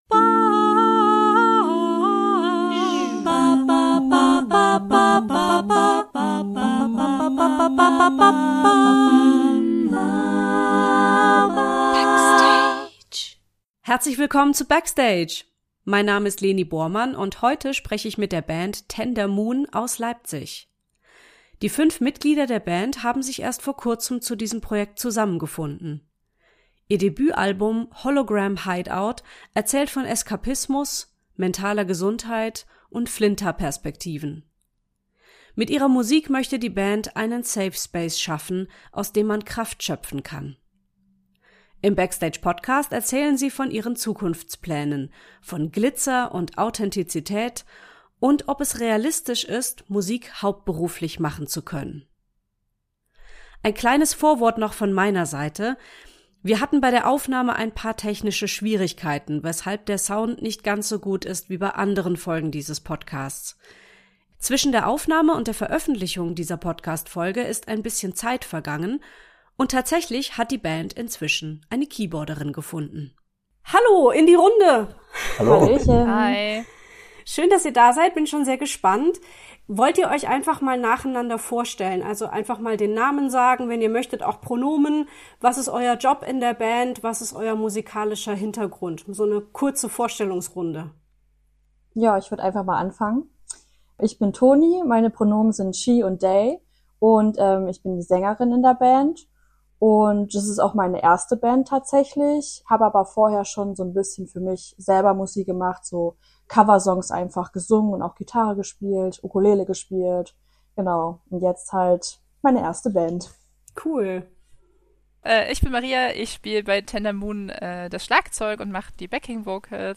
Heute spreche ich mit der Band Tender Moon aus Leipzig.